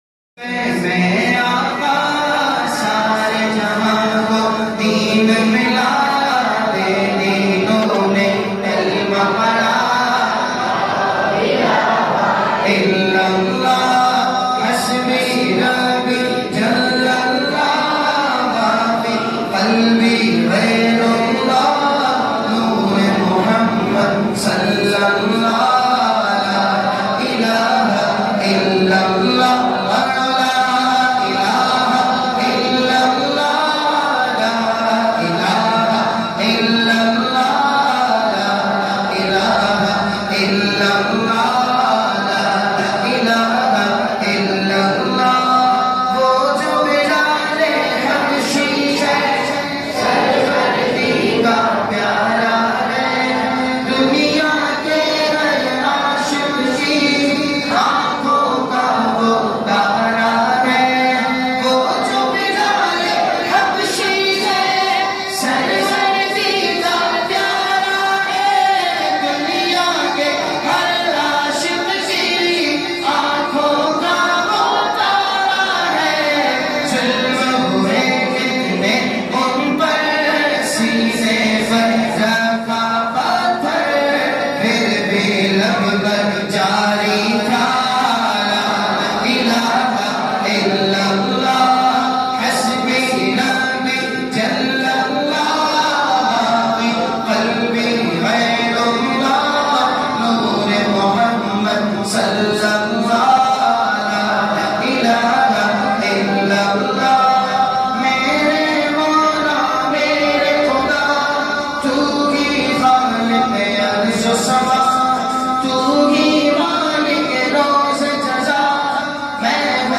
in best audio quality